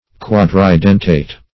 Search Result for " quadridentate" : The Collaborative International Dictionary of English v.0.48: Quadridentate \Quad`ri*den"tate\, a. [Quadri- + dentate.]
quadridentate.mp3